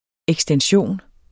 Udtale [ εgstənˈɕoˀn ]